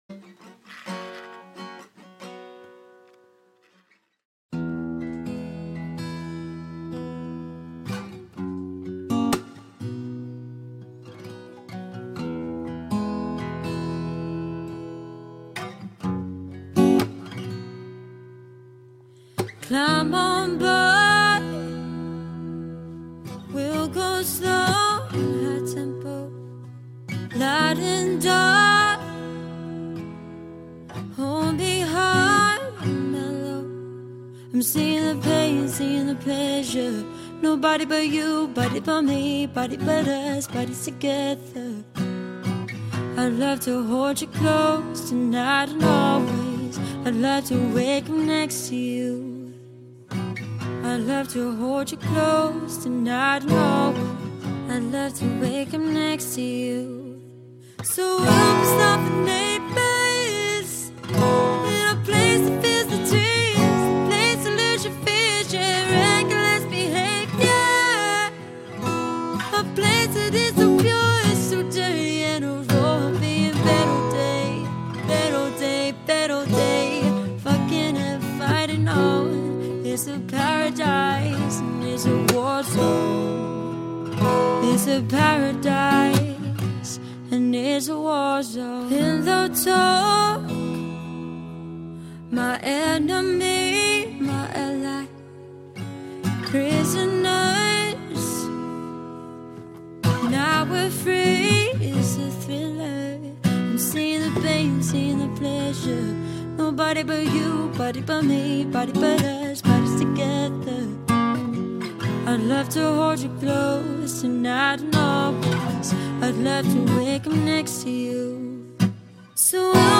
Female Solo Acoustic Guitarist for Hire